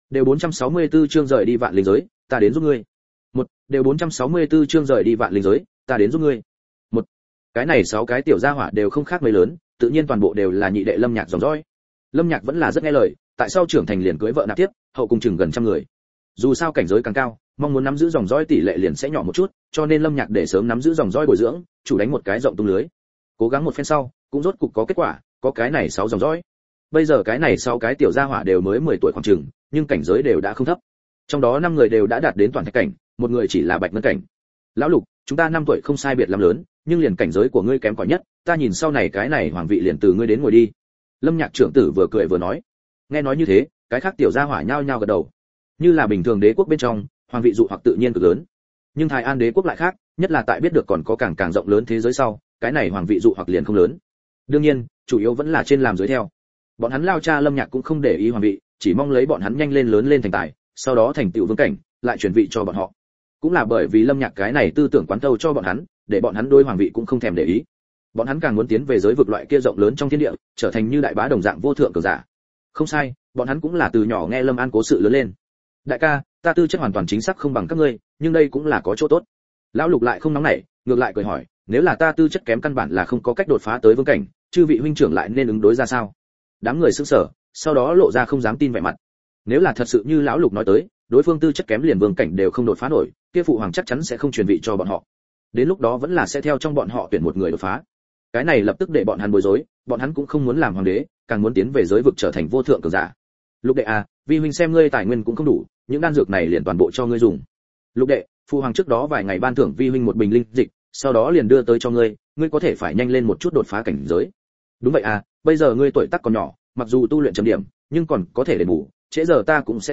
Nghe truyện Audio online Hắn Không Nói Một Lời, Chỉ Là Một Vị Địa Sát! Audio Của Tác Giả Mi Hầu Một Hữu đào: Một món tên là « Tội Uyên » trò chơi xuất hiện. Nhưng một năm sau, trò chơi giáng lâm hiện thực, vô số tội thú xâm lấn, nhân loại tràn ngập tuyệt vọng.